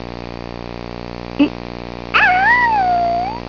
Well here are my (low quality) sounds.